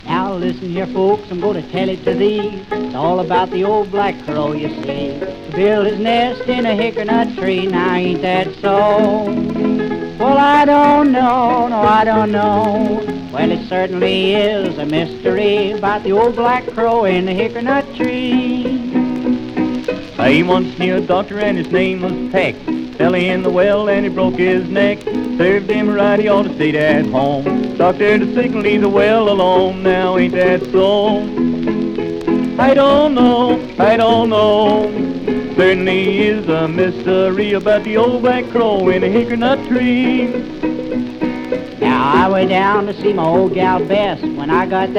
バンジョー
ギター＆カズー
Blues, Folk, World, & Country　Germany　12inchレコード　33rpm　Mono